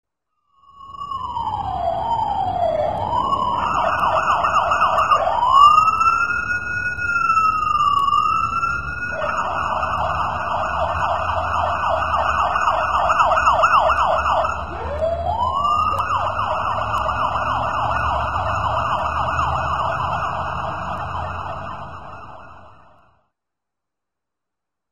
AMBULANCIA SIM635
Ambient sound effects
ambulancia_sim635.mp3